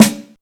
taiko-soft-hitnormal.wav